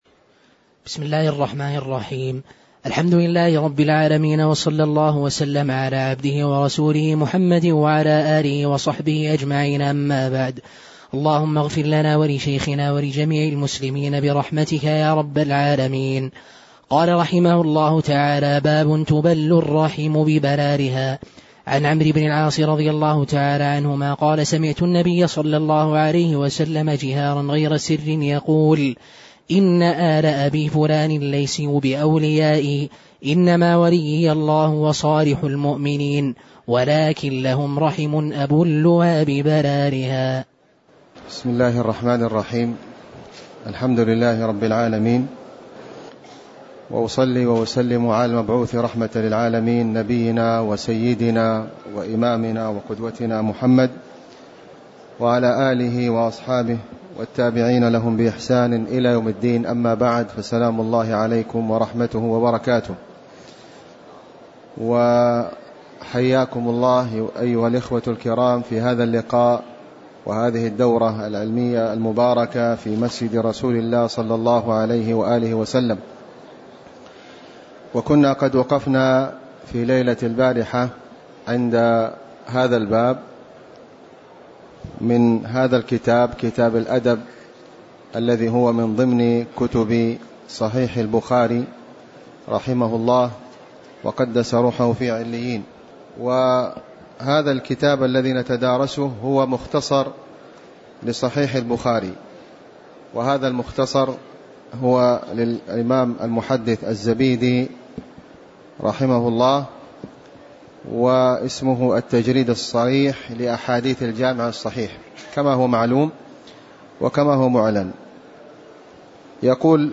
تاريخ النشر ٢٧ ربيع الثاني ١٤٣٩ هـ المكان: المسجد النبوي الشيخ: فضيلة الشيخ د. خالد بن علي الغامدي فضيلة الشيخ د. خالد بن علي الغامدي باب تُبل الرحم ببرارها (002)